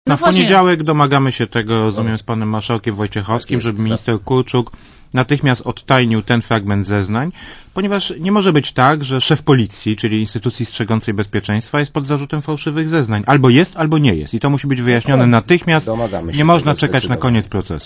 Trzeba odtajnić zeznania komendanta głównego policji - uznali w Radiu Zet politycy, wszyscy goście audycji "Siódmy Dzień Tygodnia".